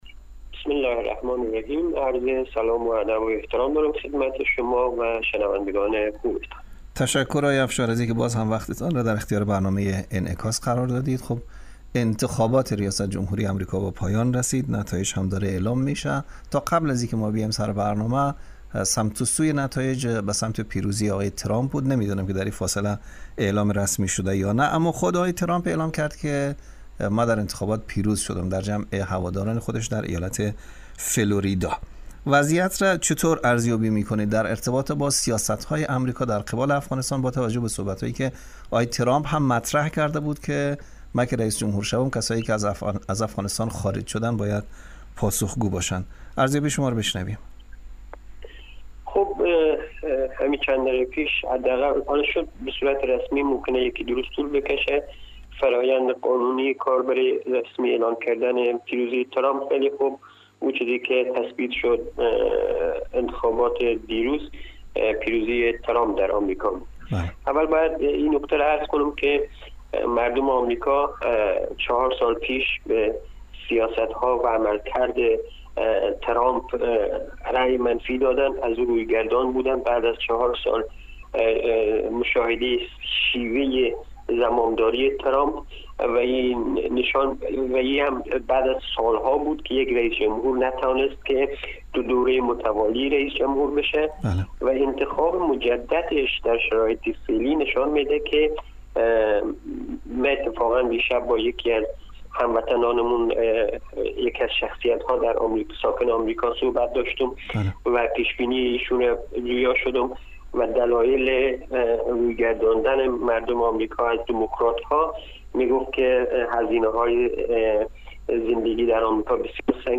در گفت و گو با برنامه انعکاس رادیو دری